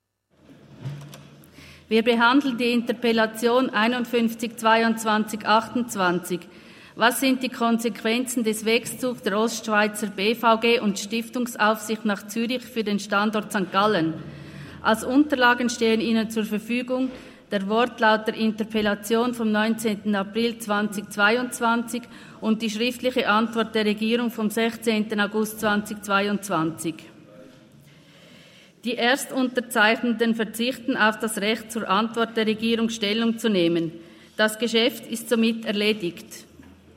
27.11.2023Wortmeldung
Session des Kantonsrates vom 27. bis 29. November 2023, Wintersession